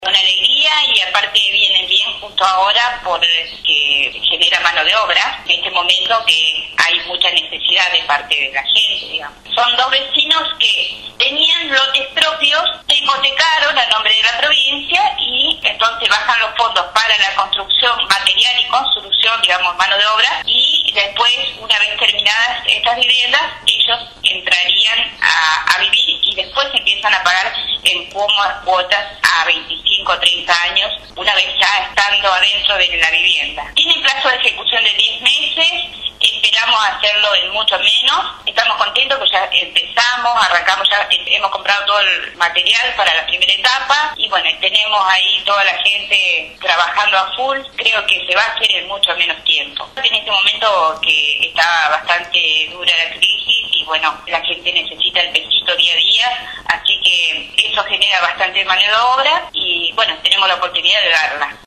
La presidenta comunal de San Bernardo, Saida Asan, dio detalles de las últimas novedades referidas a la localidad, destacando la importancia de las distintas iniciativas que están en marcha: construcción de dos viviendas, colocación de carteles nomencladores, puesta en marcha del programa Raíces, acciones para cuidar el medio ambiente, y también brindó un panorama referido a las recientes lluvias.